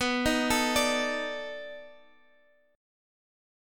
B7#9 Chord